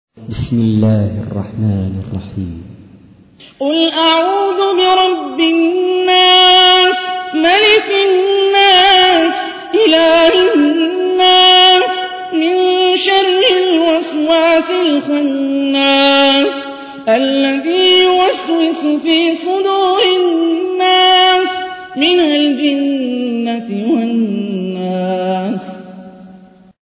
تحميل : 114. سورة الناس / القارئ هاني الرفاعي / القرآن الكريم / موقع يا حسين